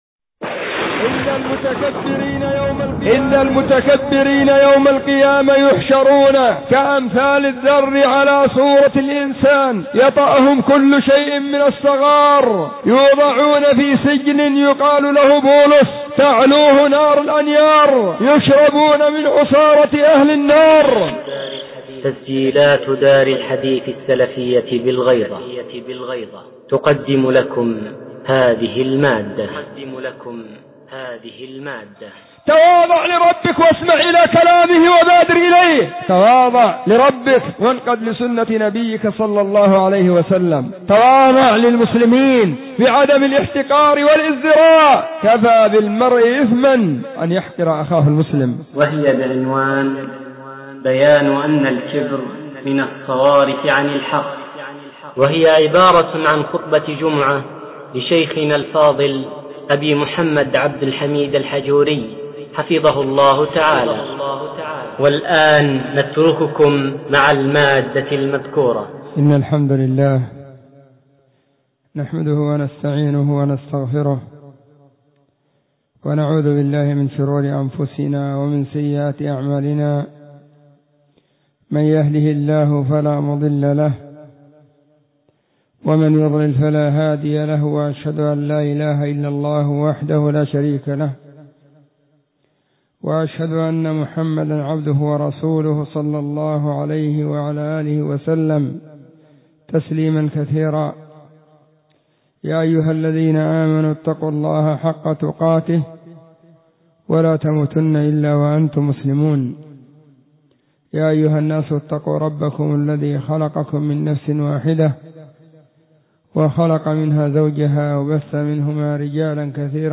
خطبة جمعة بعنوان :بيان أن الكبر من الصوارف عن الحق
📢 وكانت في مسجد الصحابة بمدينة الغيضة – اليمن.